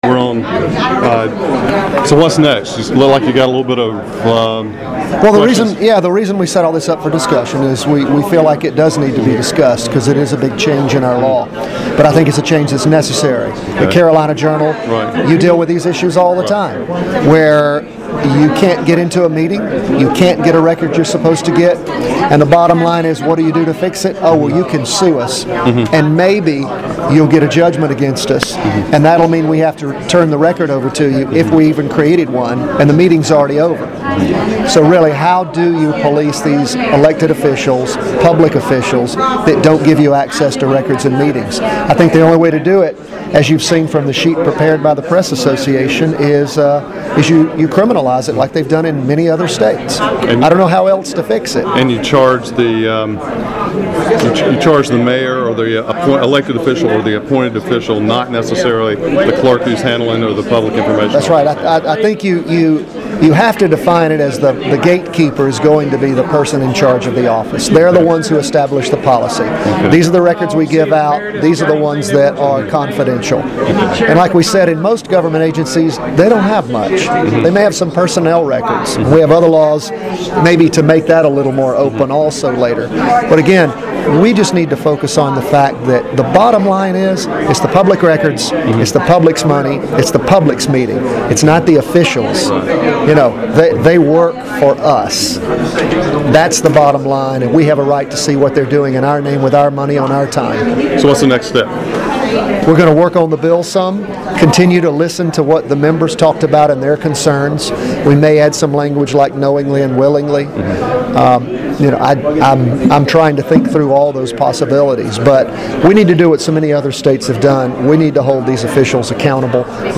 Critics say public officials should not be penalized for 'judgment calls' Audio of an interview with Sen. Thom Goolsby, R-New Hanover, after Tuesday's Senate Judiciary Committee meeting is available here .